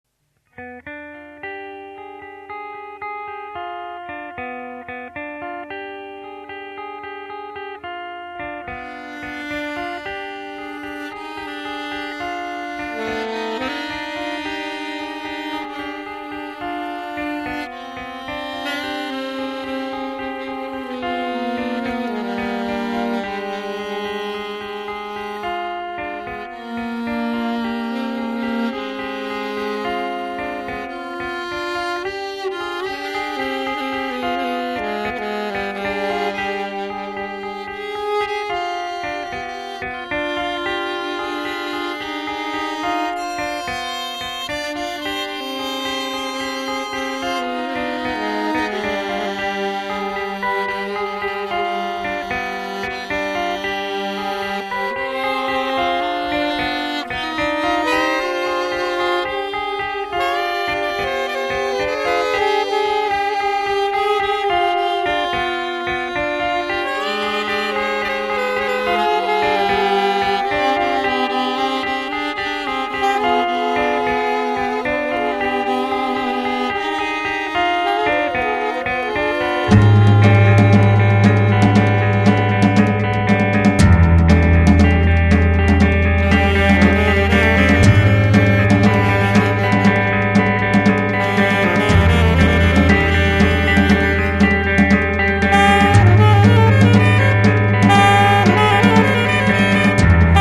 guitar and drums
avantjazz saxophonist